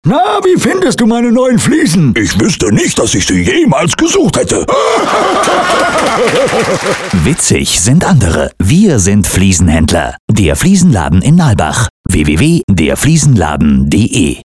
deutscher Sprecher für Werbespots, Hörspiele und Hörbücher, Podcasts, E-Journals und Business Radio Special: Münchener Dialekt Bayerisch
Sprechprobe: Industrie (Muttersprache):
german voice over talent